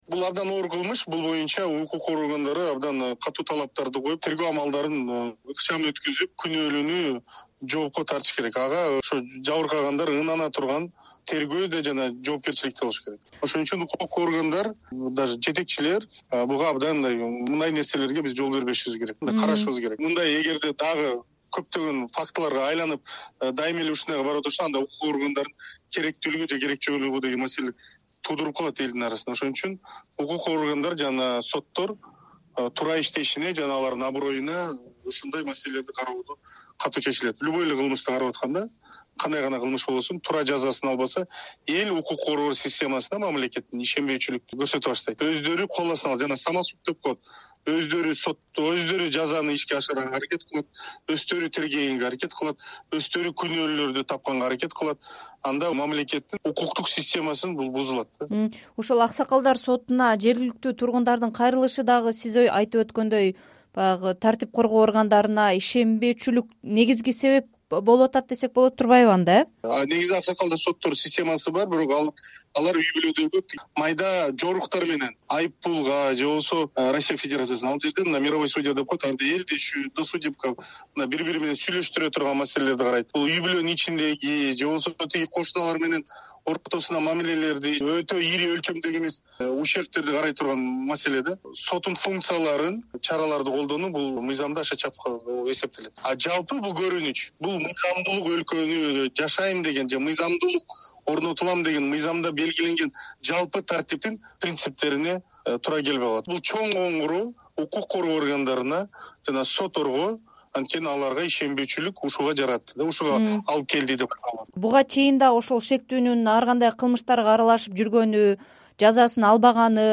Сокулук районунундагы окуя боюнча Жогорку Кеңештин депутаты Мирлан Жээнчороев "Азаттыкка" маек курду.